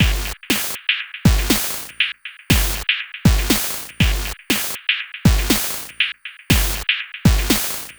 120_BPM
ChipShop_120_Combo_G#m_06.wav